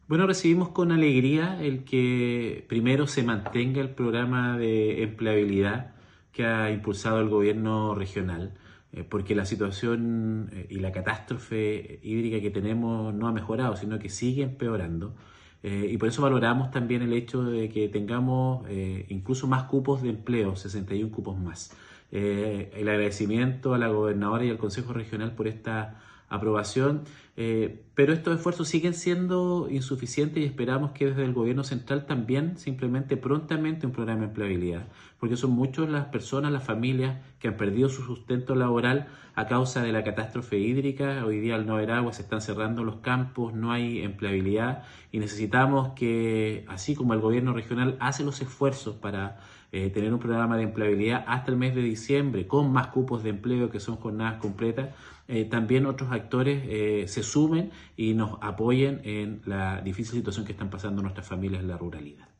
El alcalde de Monte Patria, Cristian Herrera manifestó
CUNA-ALCALDE-DE-MONTE-PATRIA-CRISTIAN-HERRERA.mp3